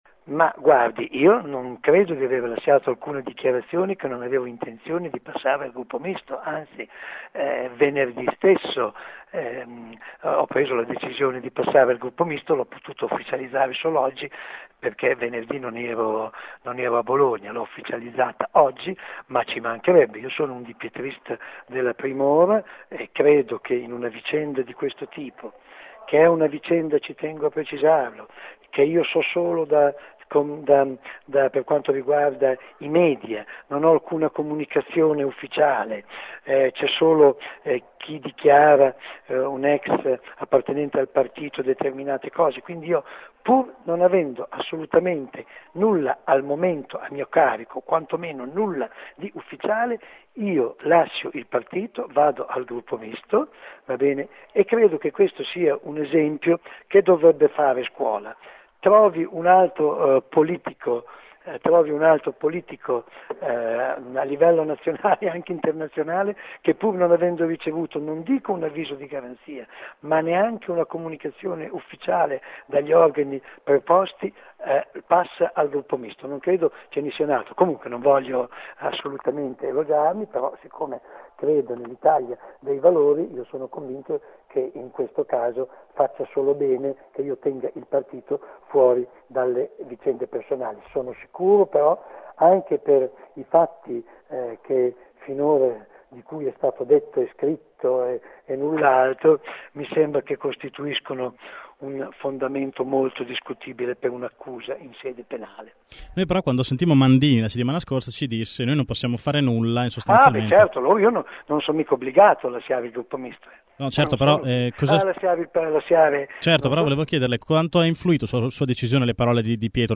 Giovedì scorso, però, ai nostri microfoni, a precisa domanda del perché non fosse passato al gruppo misto, Nanni aveva risposto: “Ma perché? Sono stato eletto nell’Italia dei valori, perché devo passare al gruppo misto?”